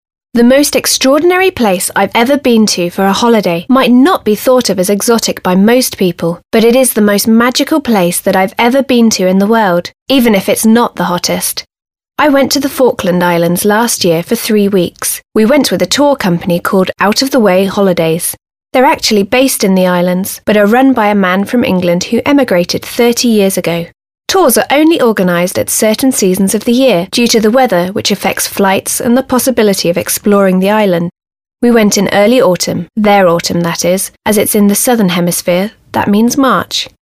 2. dialog lub monolog prezentujący nowe słówka i potrzebne zwroty